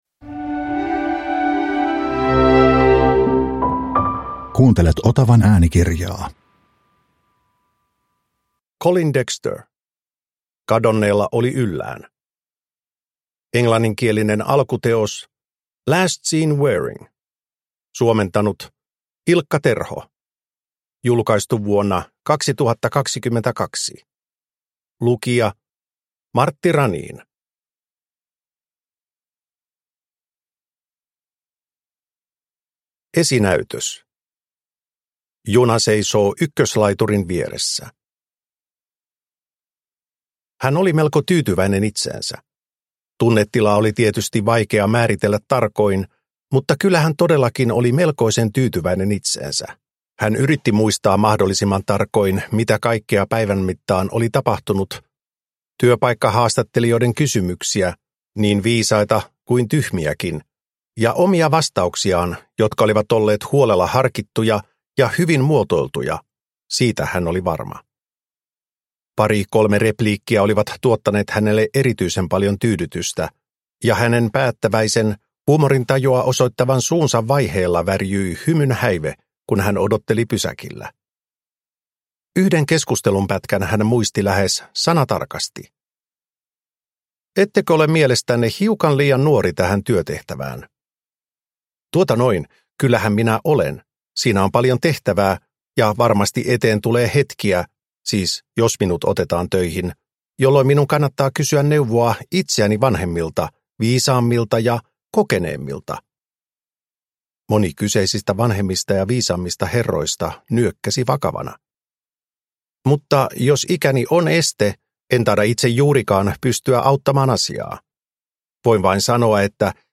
Kadonneella oli yllään – Ljudbok – Laddas ner